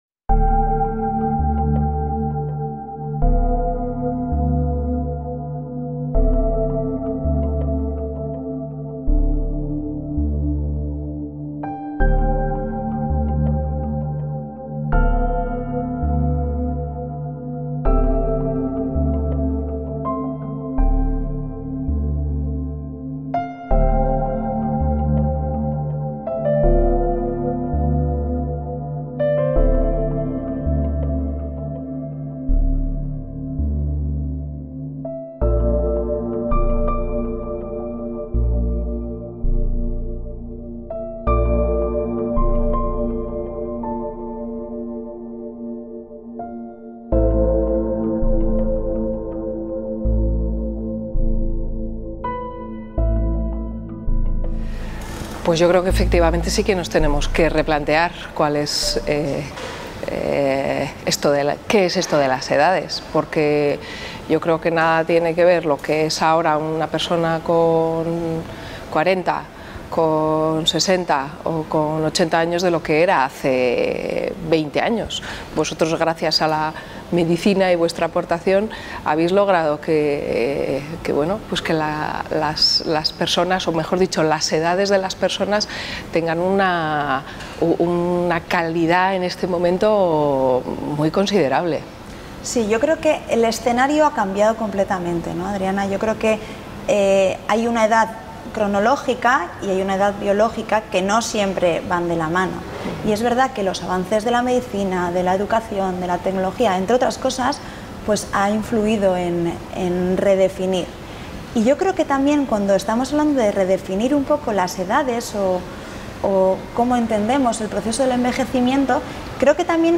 En una nueva entrega de la sección Solasaldiak, dos especialistas dialogan sobre el envejecimiento desde distintos puntos de vista: la positivización del concepto, la discriminación por edad, la vida comunitaria y la comunidad ideal, la tecnología y el envejecimiento...